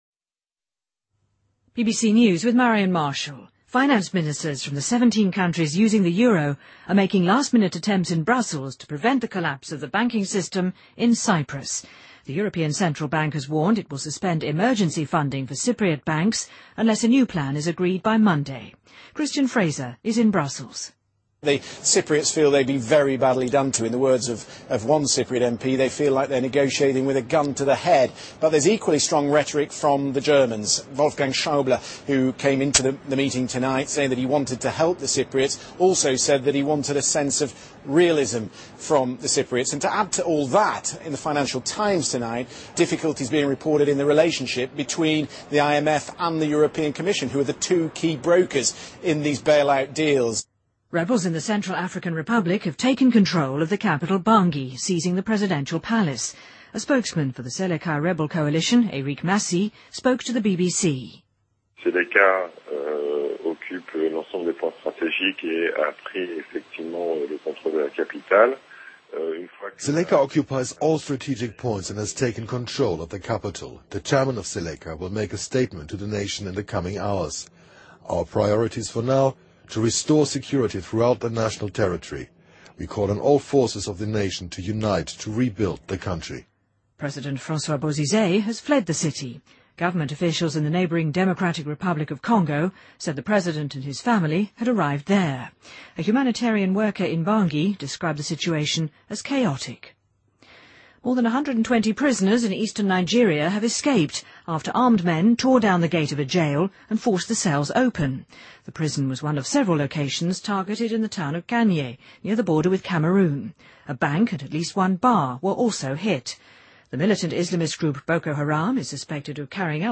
BBC news,2013-03-25